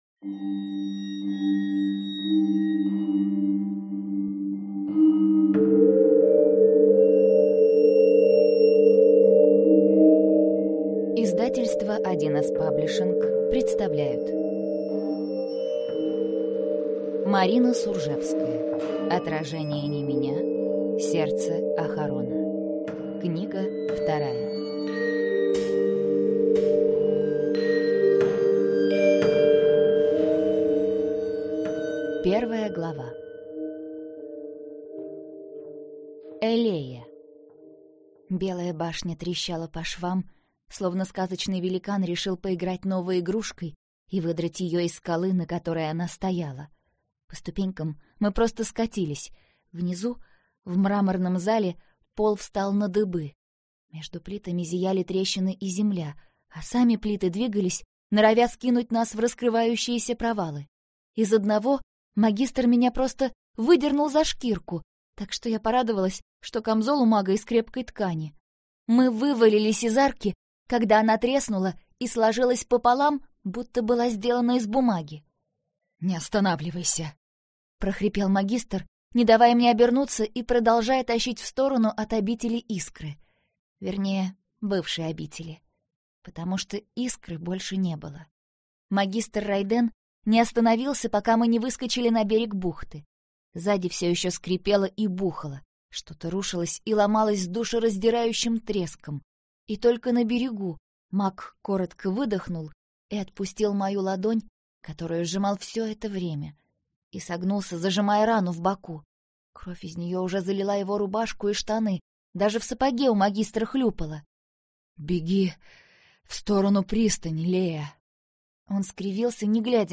Аудиокнига Отражение не меня.